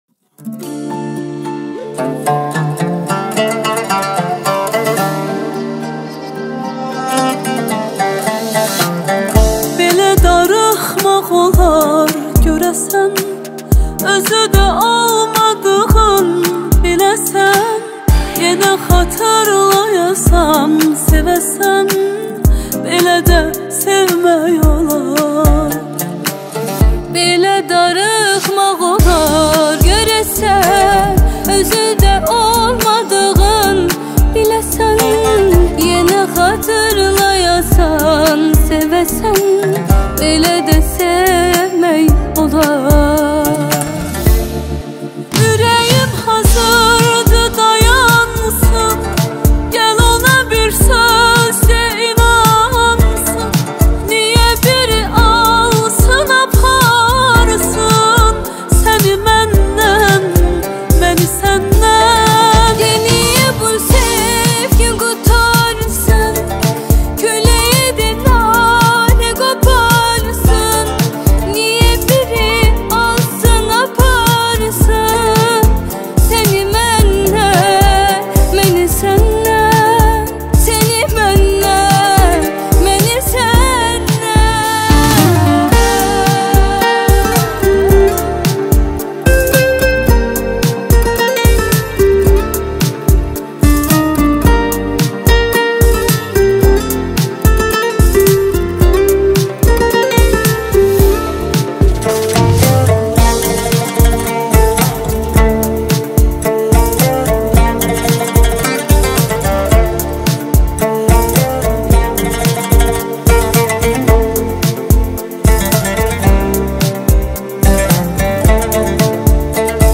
آهنگ آذربایجانی آهنگ غمگین آذربایجانی آهنگ هیت آذربایجانی